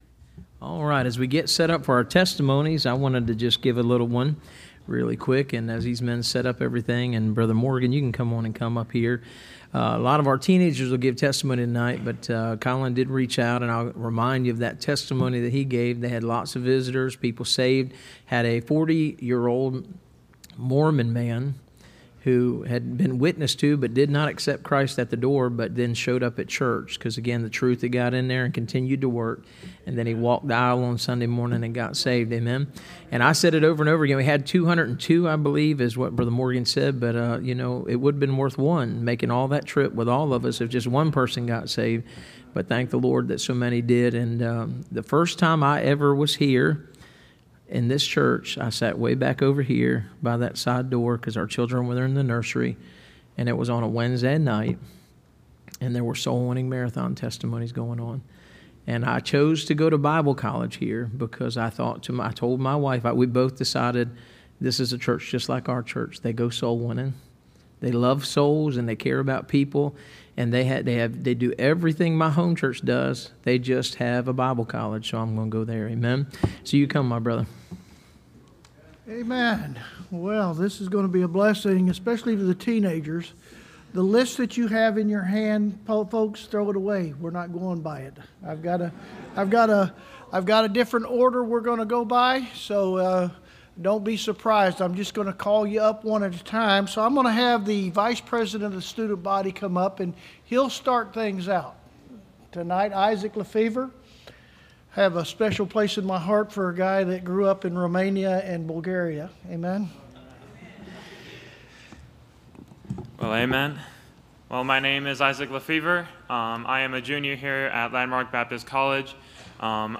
Service Type: Wednesday College